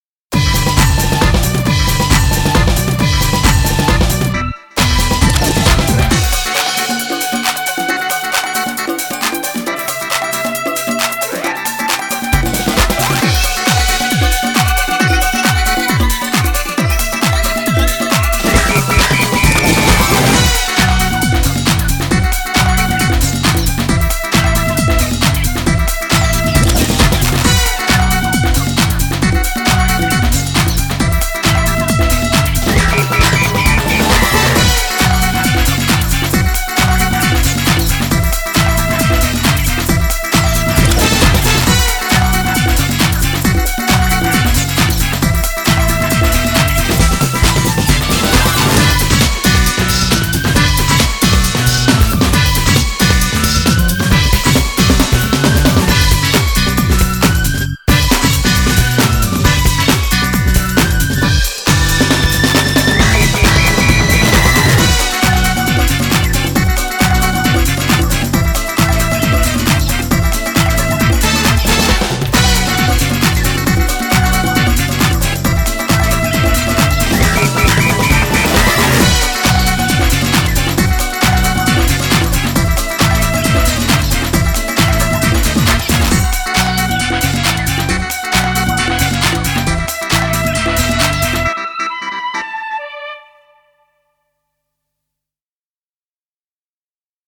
BPM135
Audio QualityPerfect (High Quality)
Genre: WORLD HOUSE.